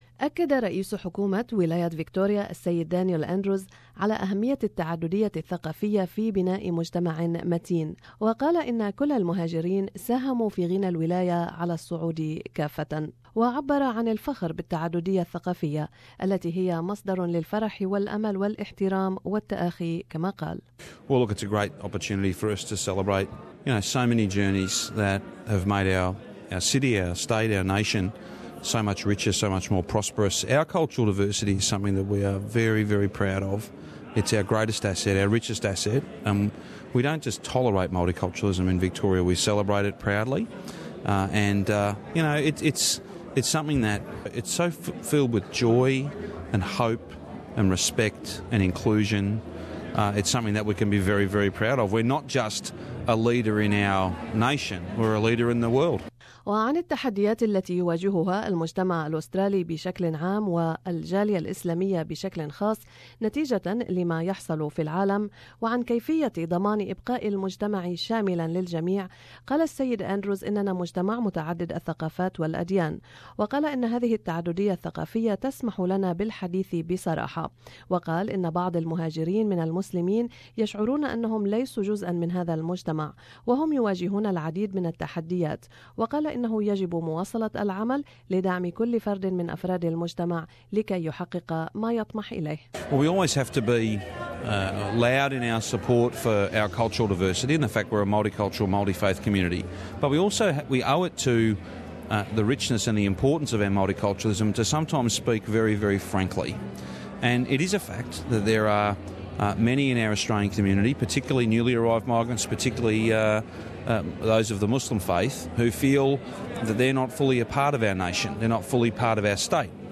During the Premier's Gala Dinner, a yearly event that celebrates cultural diversity in the State of Victoria, we spoke to the Victorian Premier the Honourable Daniel Andrews. Mr Andrews reaffirmed that multiculturalism and inclusion are two aspects that help protecting our society.
We spoke also to the Leader of the Opposition, the Honourable Mathew Guy. Mr Guy praised multiculturalism and said Islam is one of the oldest religions in Australia.